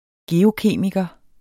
Udtale [ ˈgeːoˌkeˀmigʌ ]